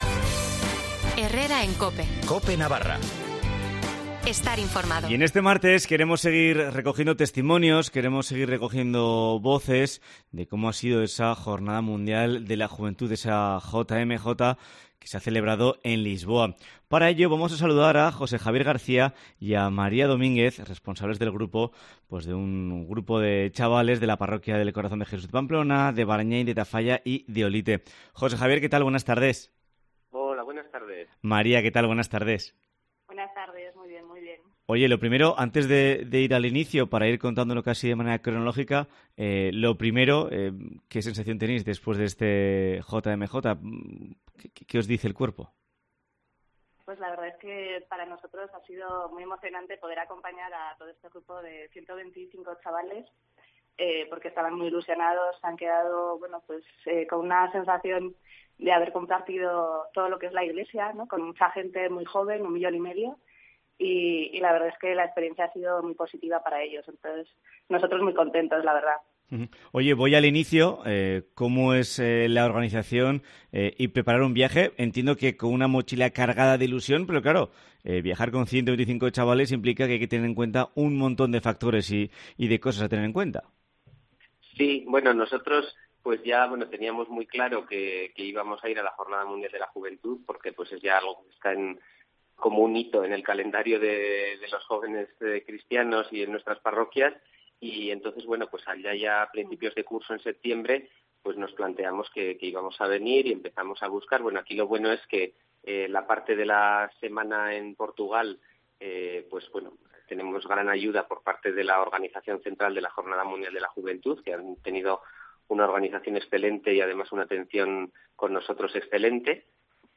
En la entrevista de COPE Navarra nos han dejado sus reflexiones.